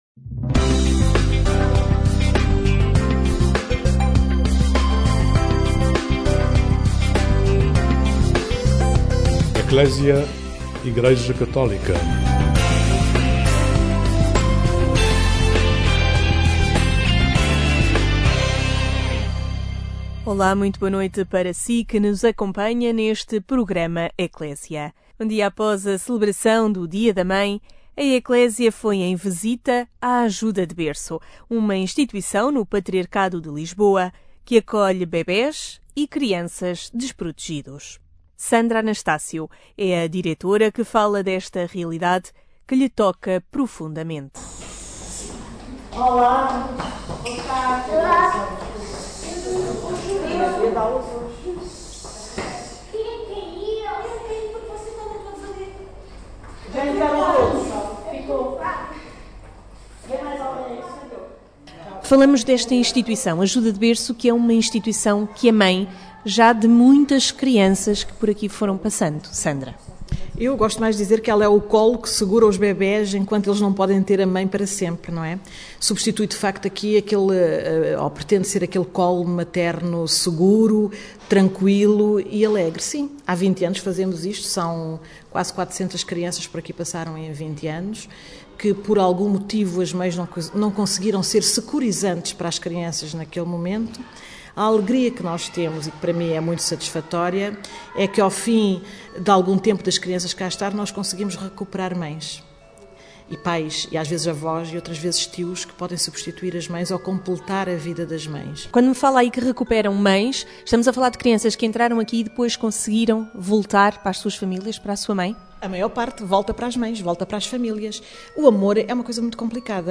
Ainda o tema da maternidade, depois de assinalar o dia da mãe, no passado domingo. O programa Ecclesia esteve na Ajuda de Berço, uma instituição que acolhe crianças desprotegidas no patriarcado de Lisboa.